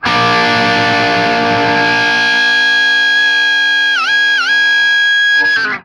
TRIAD A#  -L.wav